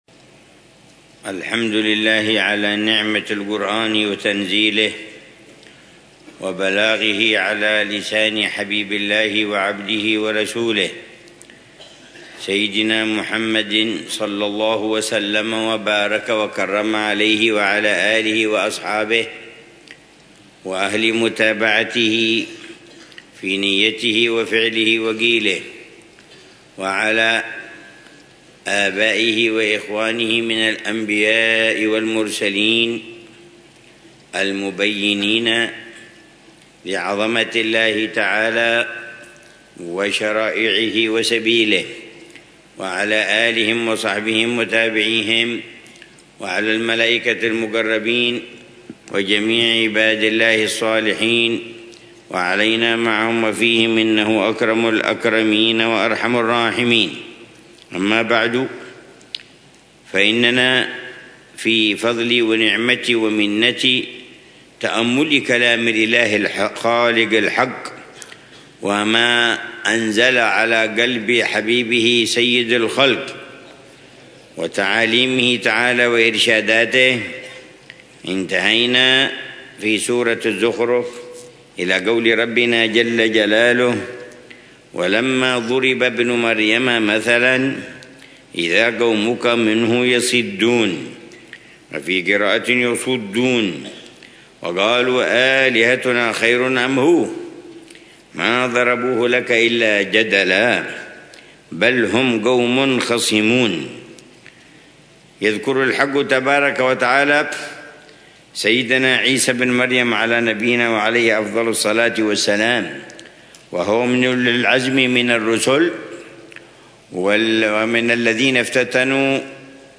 الدرس السابع من تفسير العلامة عمر بن محمد بن حفيظ للآيات الكريمة من سورة الزخرف، ضمن الدروس الصباحية لشهر رمضان المبارك من عام 1446هـ